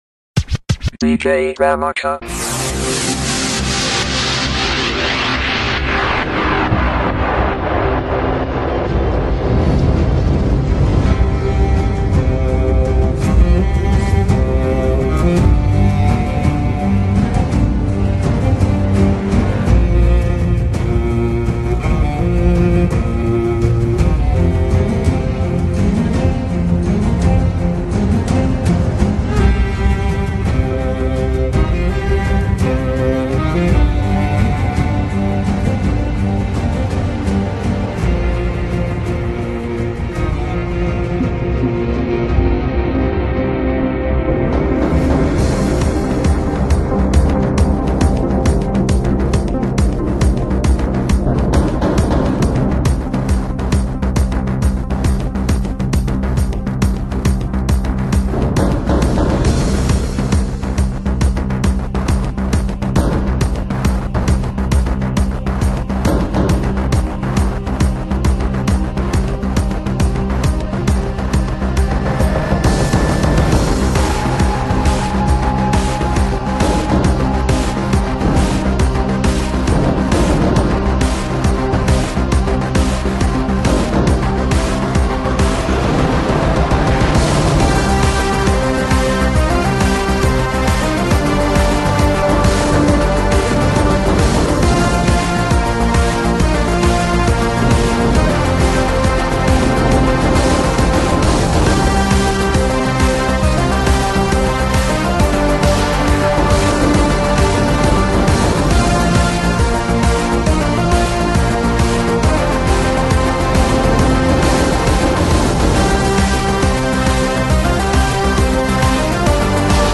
EDM Remake